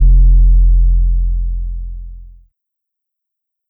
808s
pbs - d4l [ Sub ].wav